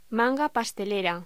Locución: Manga pastelera
voz